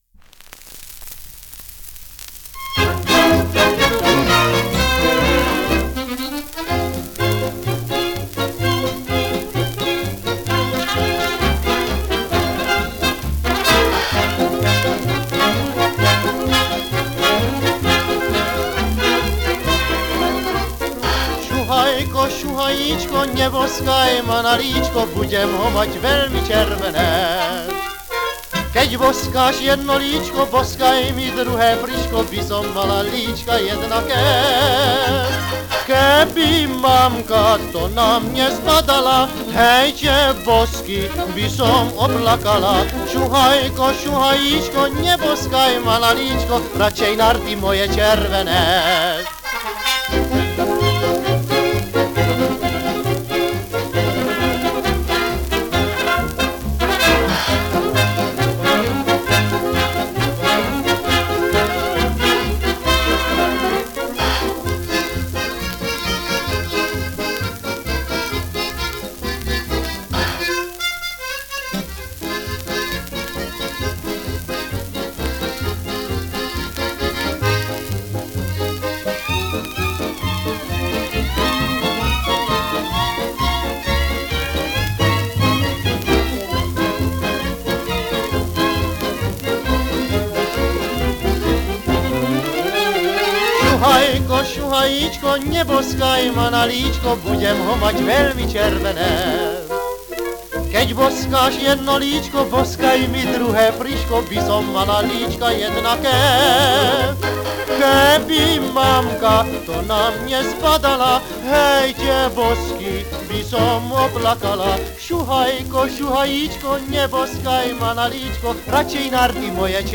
Dátum a miesto nahrávania: 20.10.1937, Praha
Slovenský foxtrot
Praha Popis Mužský spev so sprievodom orchestra.